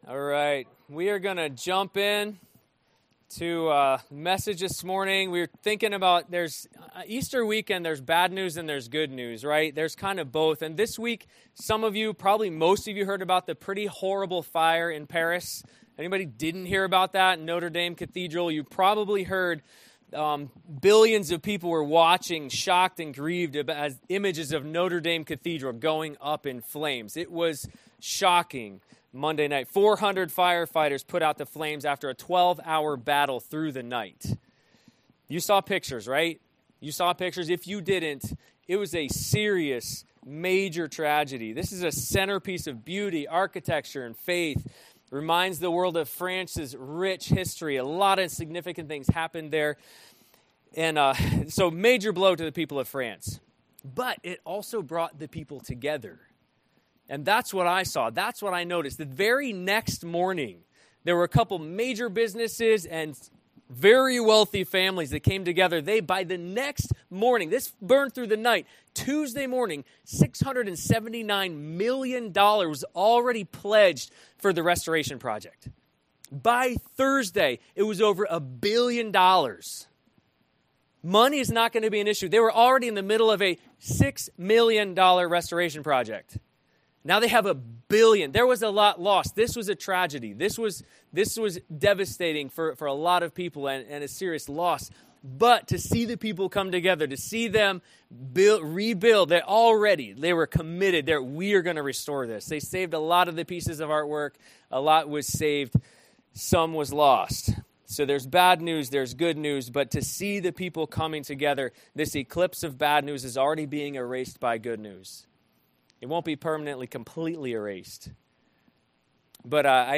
Series: CCC Sermons
Service Type: Sunday Morning